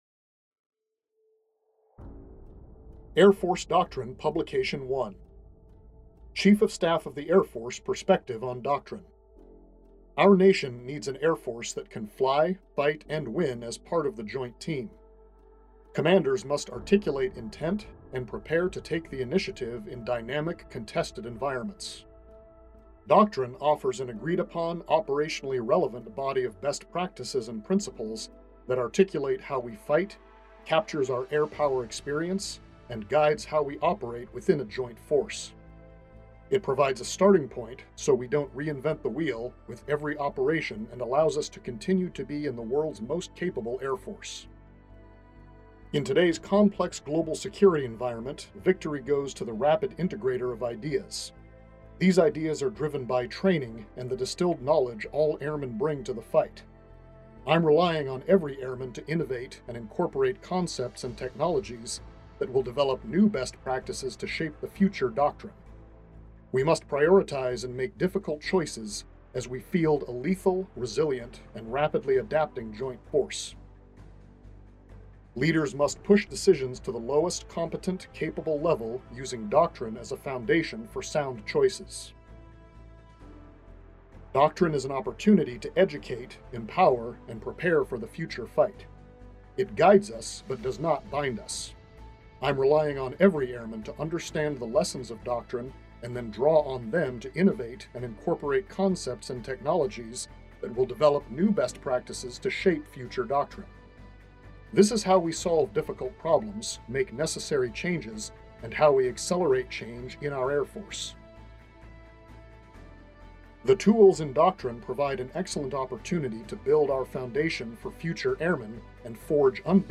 AFDP-1 Audiobook.mp3